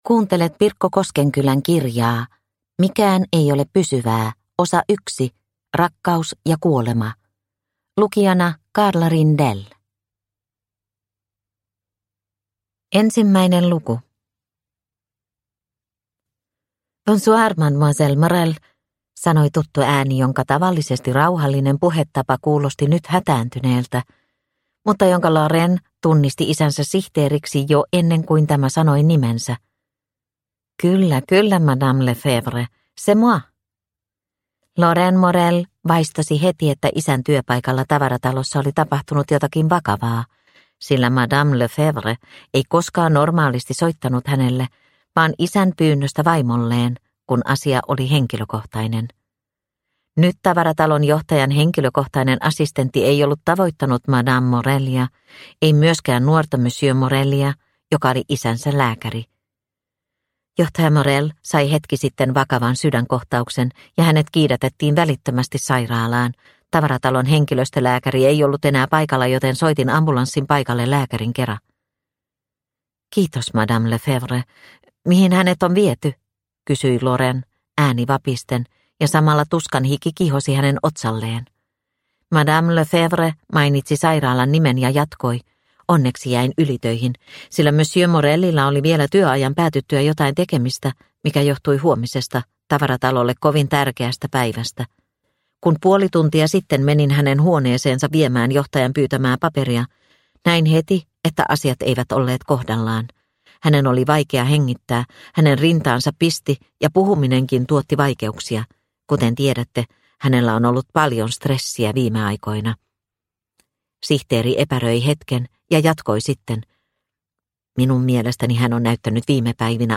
Mikään ei ole pysyvää – Ljudbok – Laddas ner